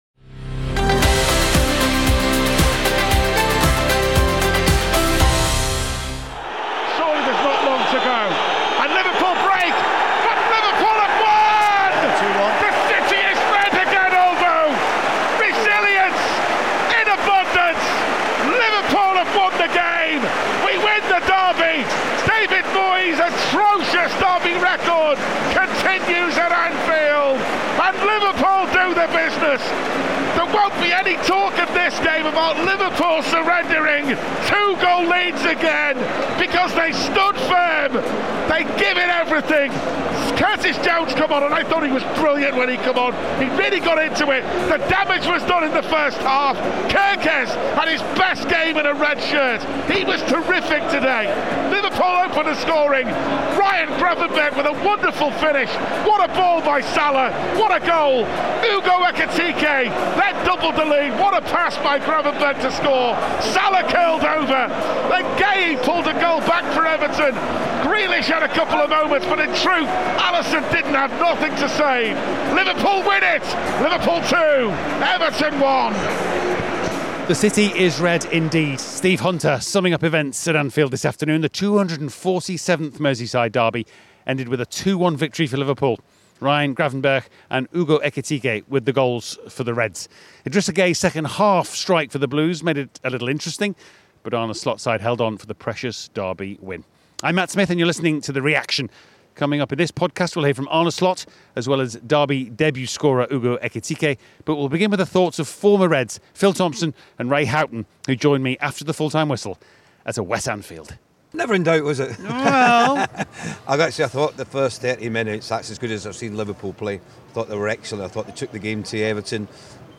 In this episode of the Reaction, we'll hear from goalscorer Ekitike as well as Liverpool boss Arne Slot; we also get the thoughts of former Reds Phil Thompson and Ray Houghton.